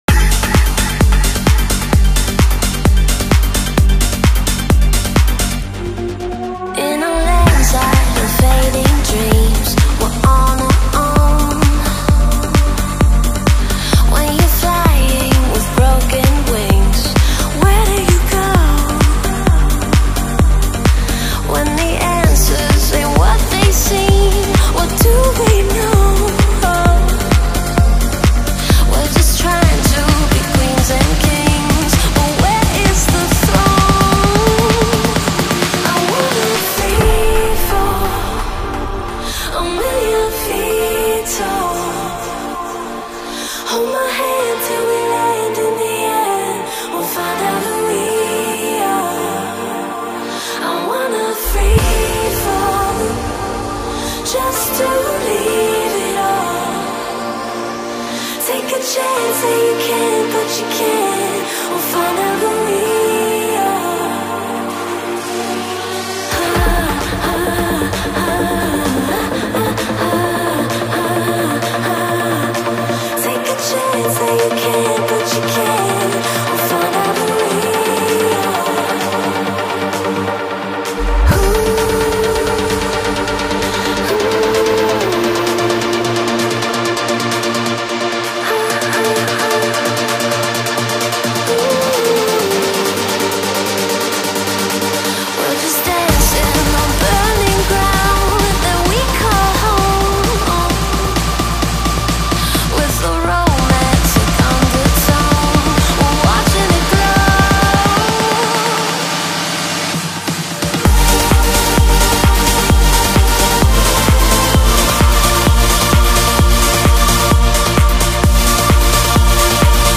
BPM65-130
Comments[TRANCE]